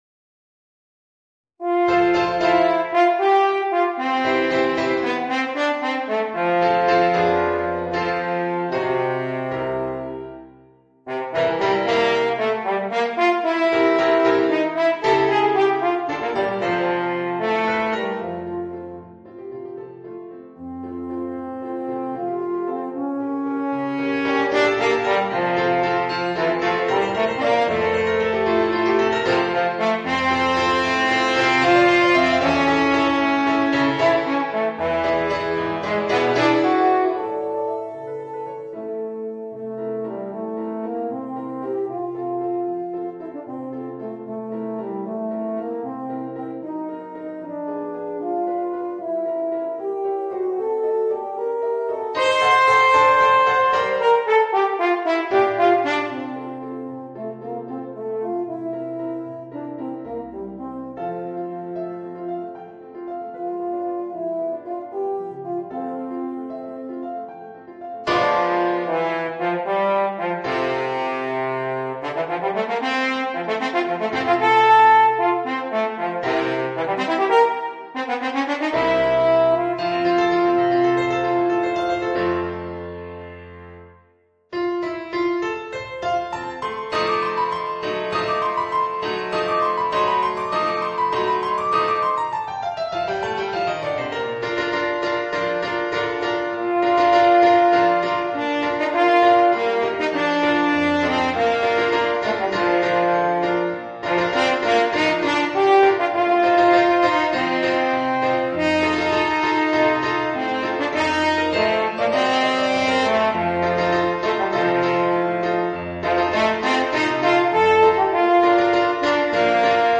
Es-Horn & Klavier